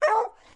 比格犬男孩叫声 " 比格犬叫声喷出室内硬墙 06
描述：这是一只小猎犬制作比格犬树皮的录音。
Tag: 树皮 小猎犬